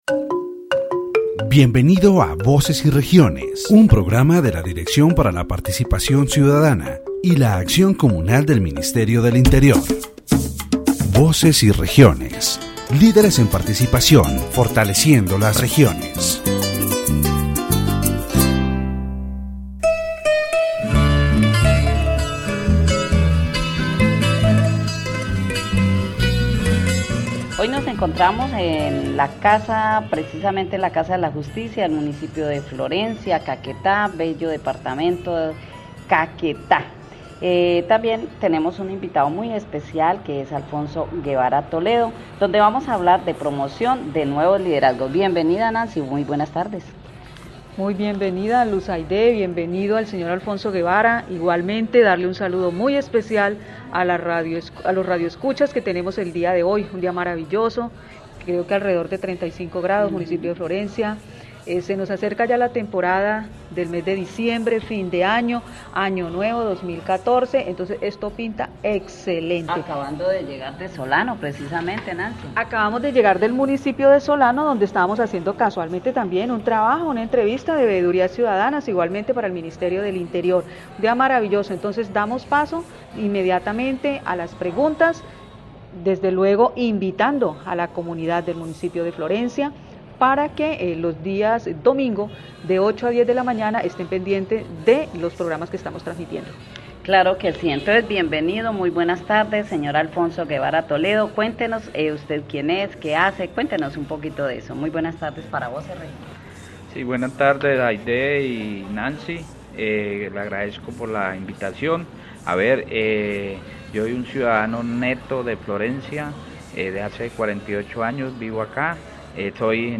The radio program "Voces y Regiones" of the Ministry of the Interior is broadcast from Florencia, Caquetá, on station 98.1. In this episode, the topic of promoting new leadership and the importance of citizen participation in community action boards is addressed.